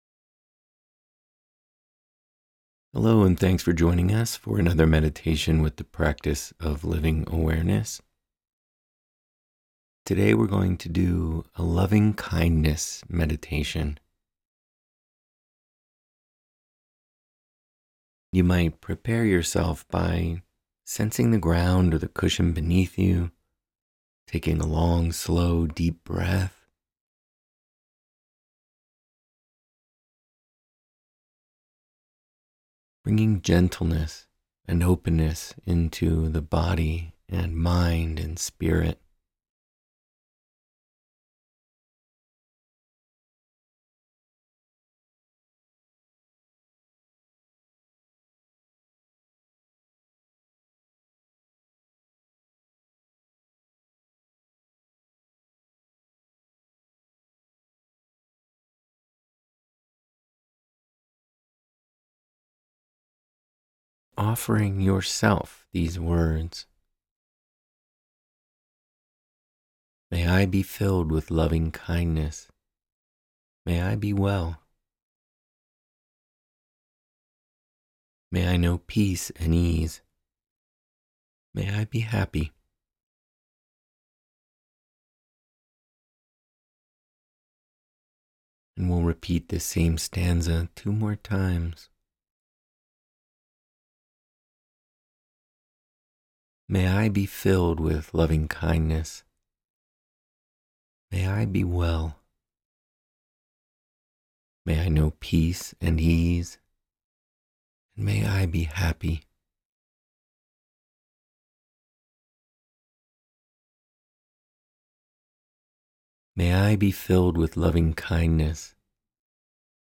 Today we practice with a loving kindness meditation.